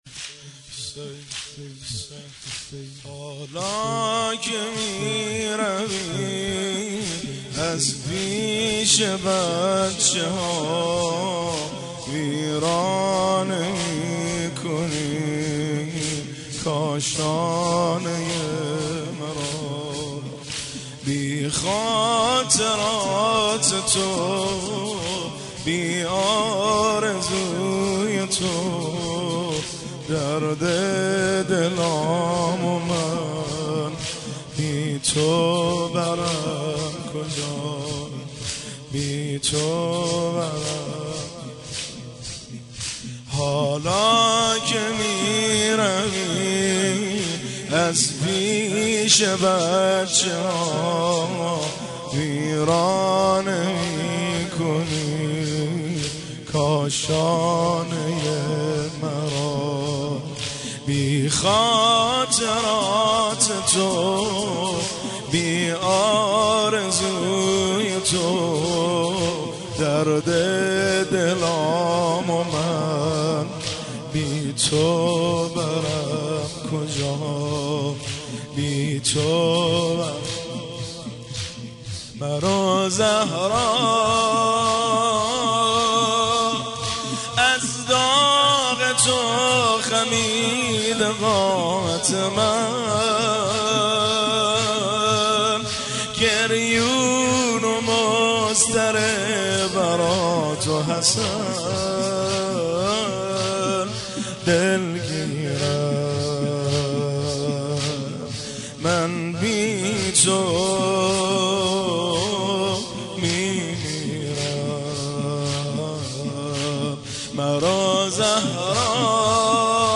سینه زنی ویژه ایام فاطمیه
مداح
عنوان : سینه زنی ایام فاطمیه